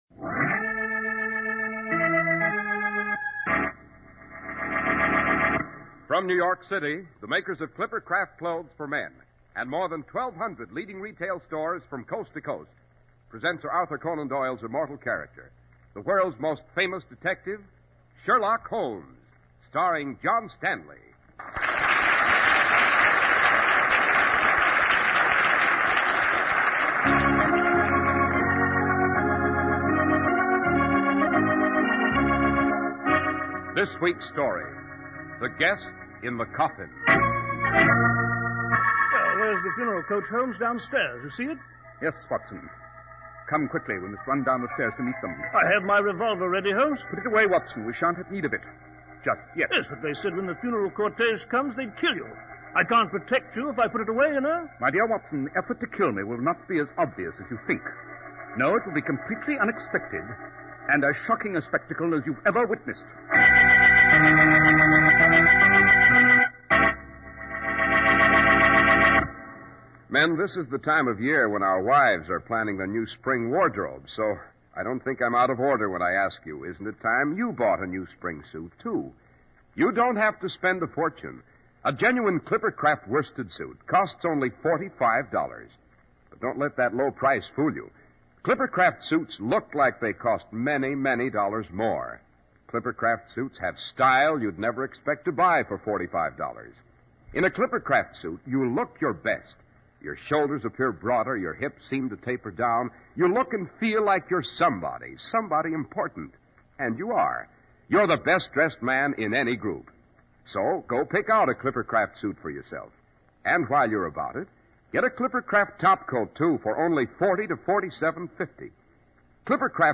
Radio Show Drama with Sherlock Holmes - The Guest In The Coffin 1949